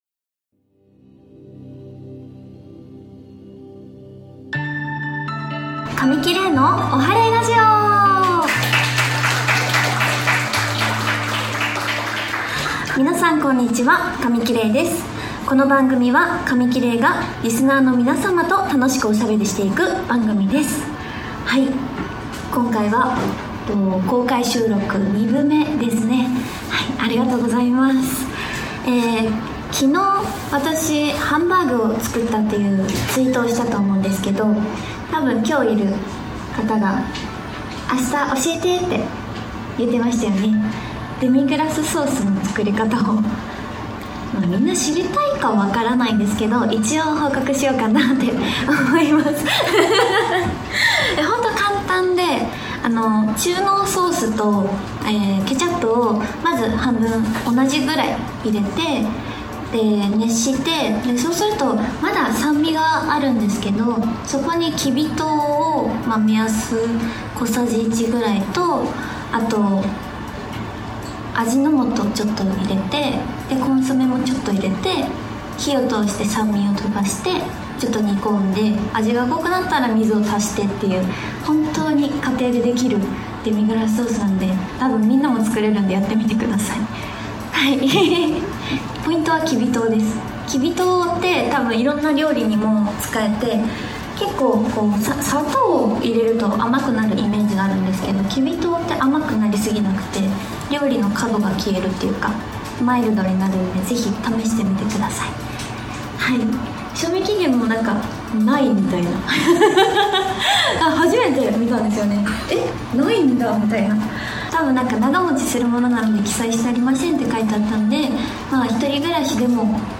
公開収録後半の部も多くの方にお越しいただきました！今回もれいさんのプライベートな一面など貴重なお話が聞けちゃいます♪